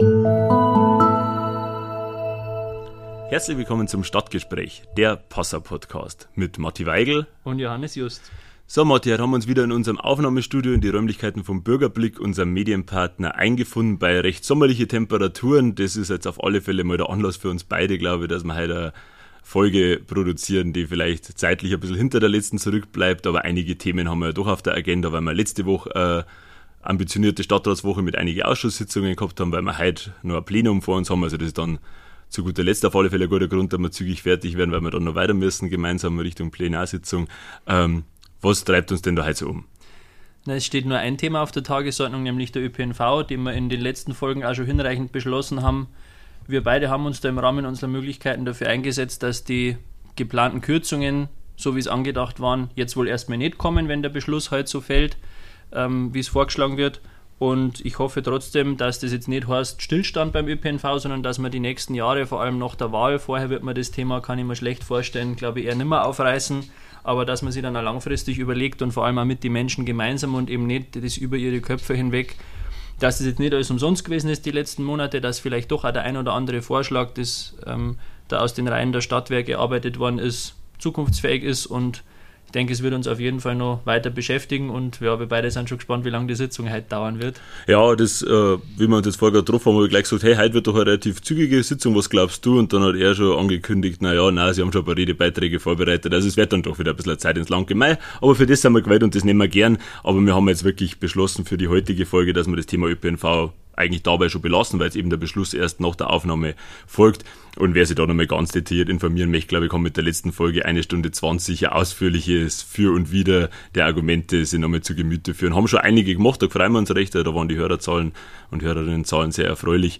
Johannes Just (SPD) und Matthias Weigl (Grüne) sind die Jüngsten im Passauer Stadtrat – ihre Debatte zur Stadtratssondersitzung zum Öffentlichen Nahverkehr.